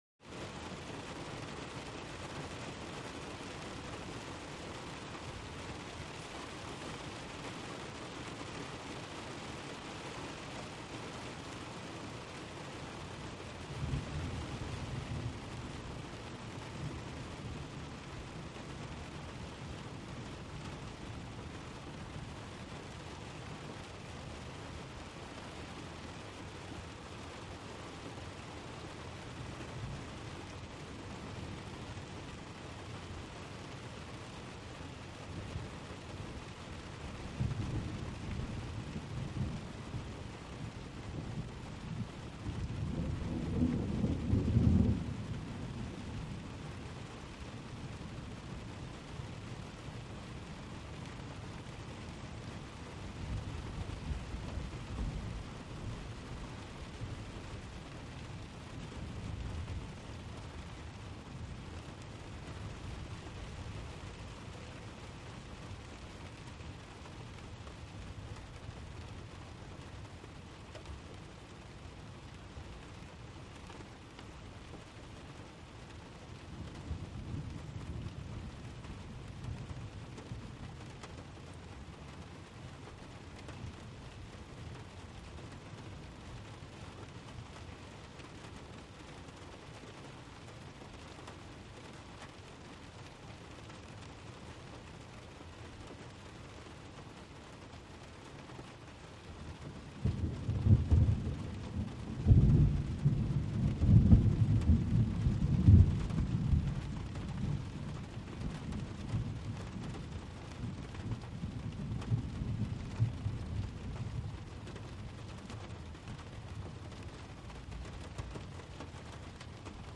Car Roof | Sneak On The Lot
Rain; Light To Medium, On Car Roof With Faint Thunder.